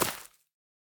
Minecraft Version Minecraft Version latest Latest Release | Latest Snapshot latest / assets / minecraft / sounds / block / azalea / break6.ogg Compare With Compare With Latest Release | Latest Snapshot
break6.ogg